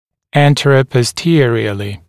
[ˌæntərəpɔs’tɪərɪəlɪ][ˌэнтэрэпос’тиэриэли]в переднезаднем направлении, в сагиттальной плоскости